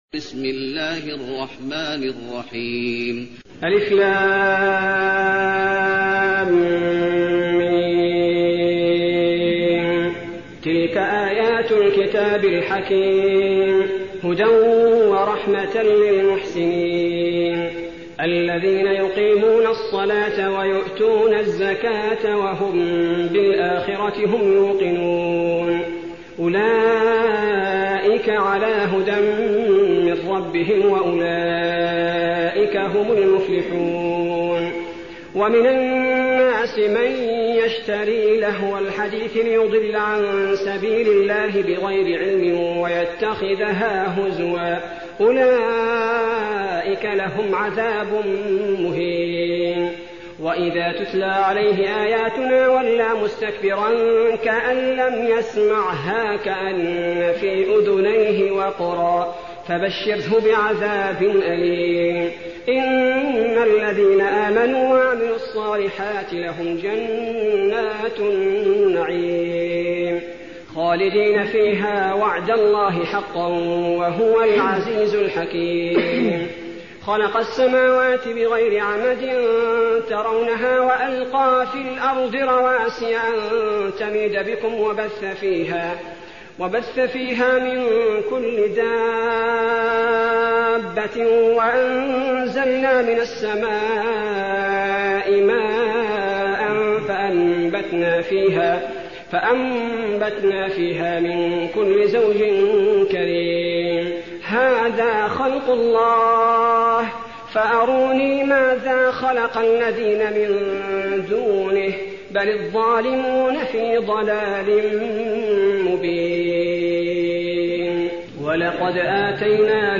المكان: المسجد النبوي لقمان